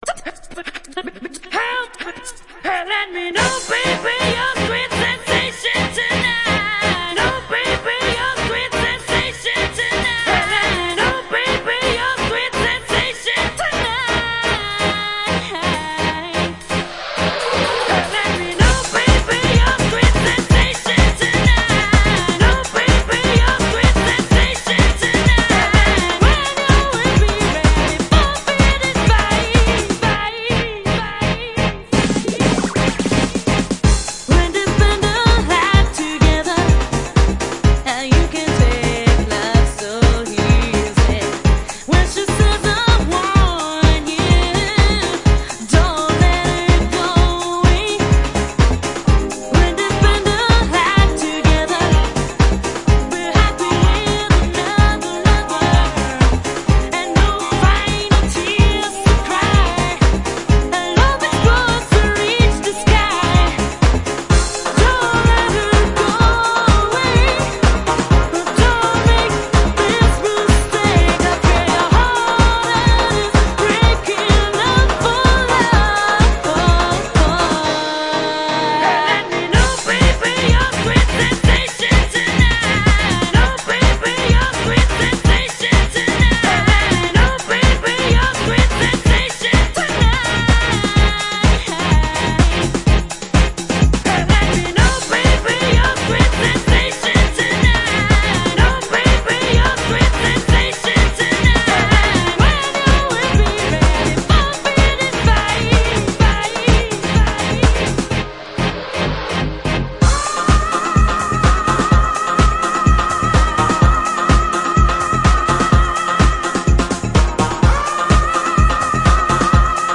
LEAD VOCALS
- BASS & DRUMS